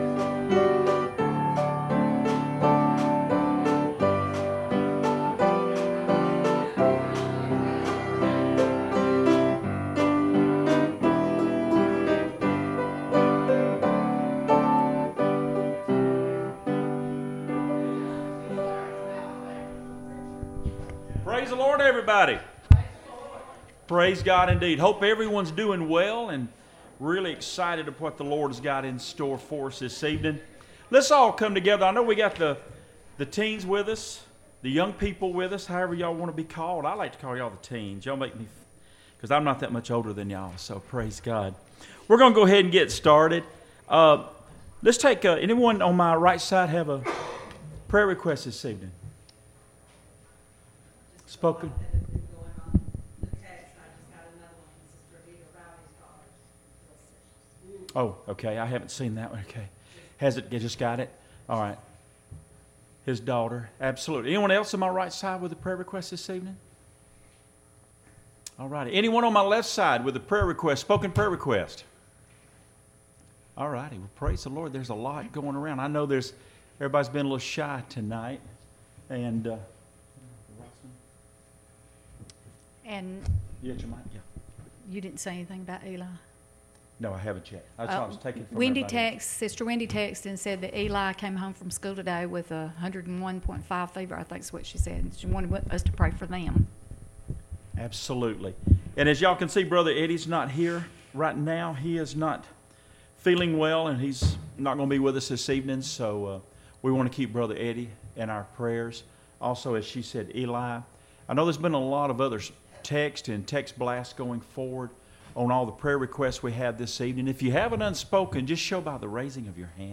Testimony Service
Service Type: Wednesday Evening Services